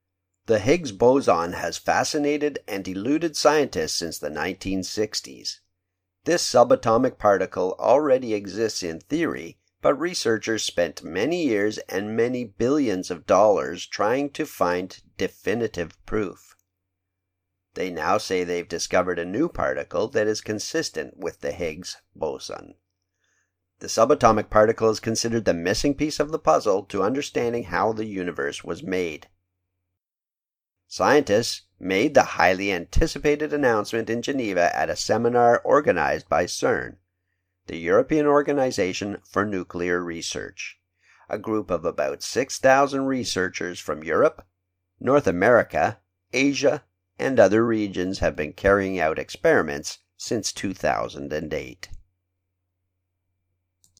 ボイスレコ ボイスサンプル